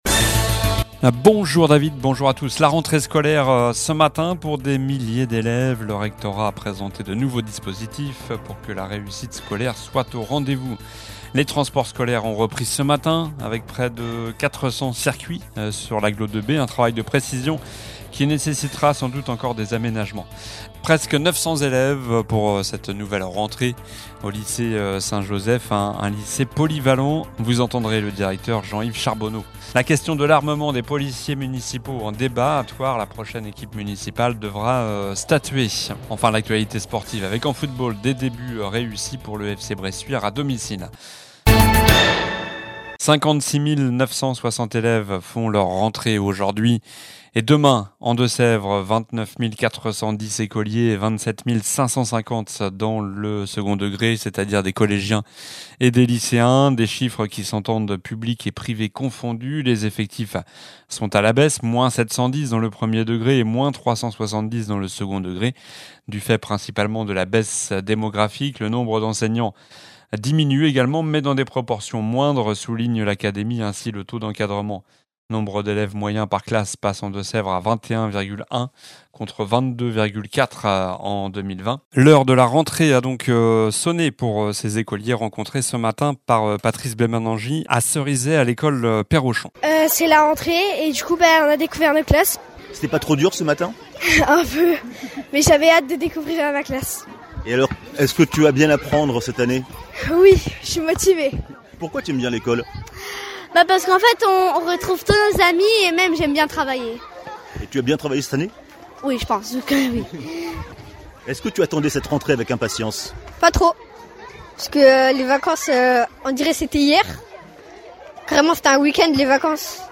Journal du lundi 1er septembre